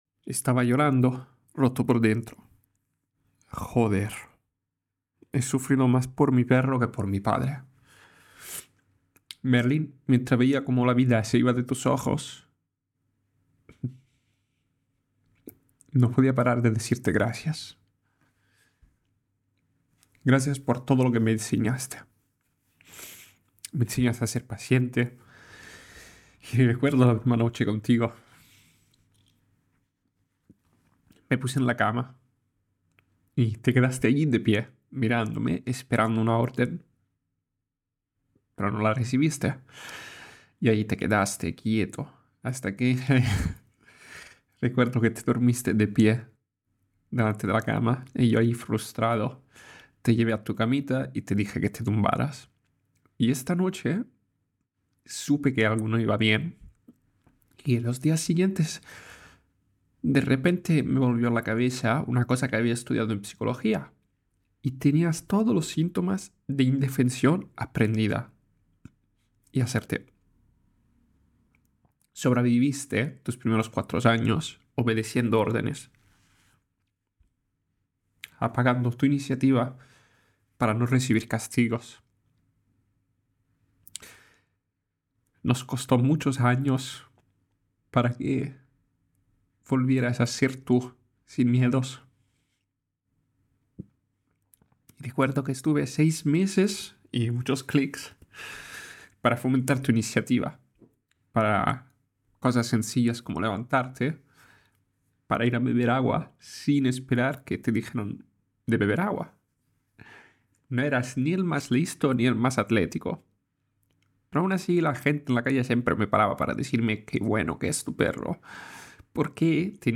Con lágrimas a los ojos, en este episodio comparto la historia de Merlín, mi compañero de vida y gran maestro.
(Episodio especial de Sactia – una conversación íntima sobre amor, muerte y presencia.)